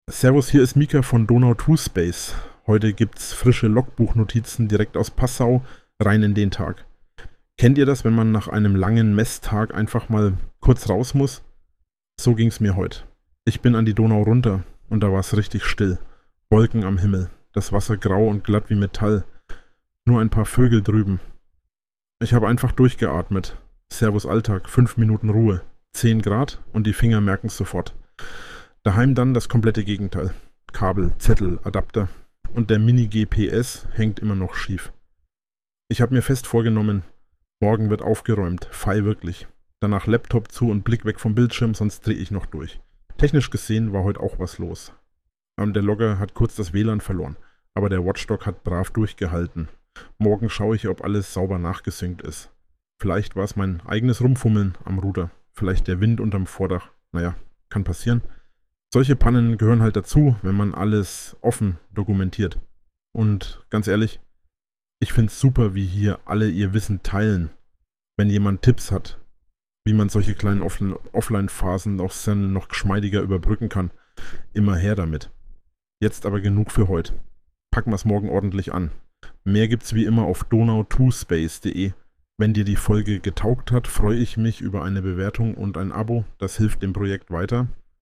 Hinweis: Dieser Inhalt wurde automatisch mit Hilfe von KI-Systemen (u. a. OpenAI) und Automatisierungstools (z. B. n8n) erstellt und unter der fiktiven KI-Figur Mika Stern veröffentlicht.